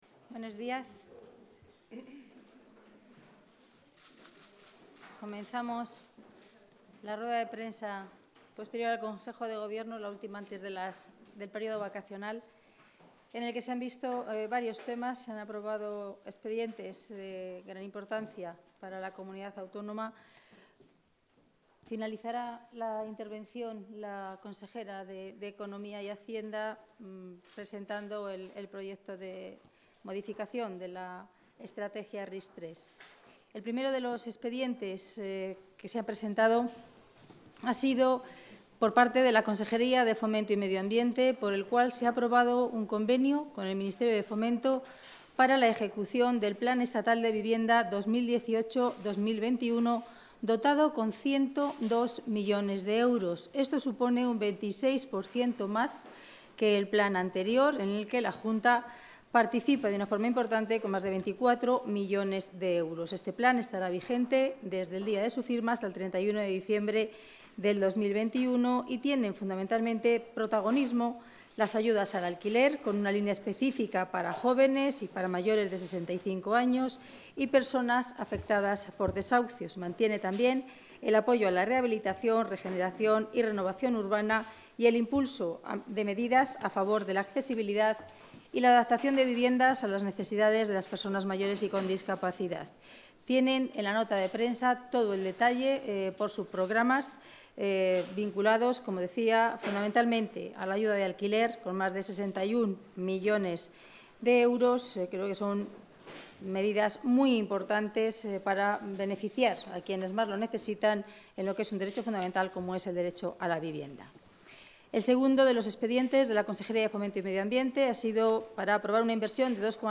Audio rueda de prensa.
Consejo de Gobierno del 26 de julio de 2018.